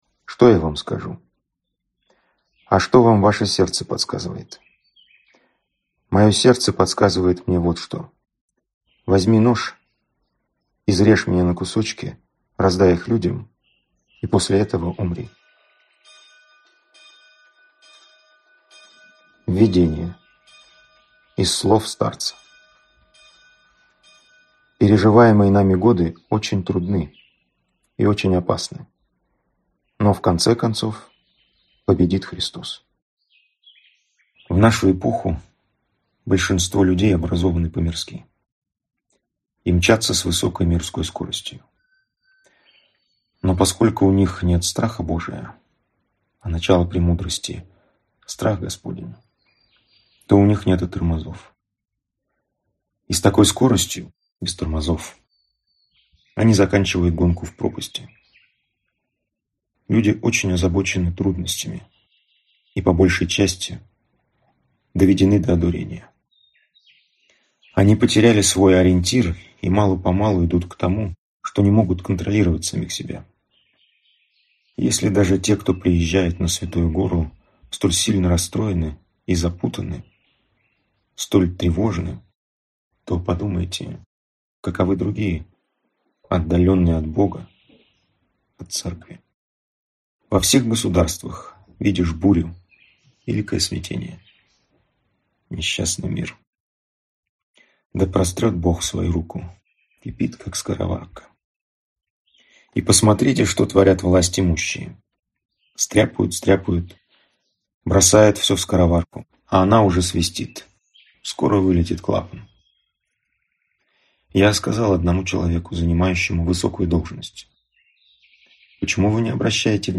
Аудиокнига Слова. Том I. С болью и любовью о современном человеке | Библиотека аудиокниг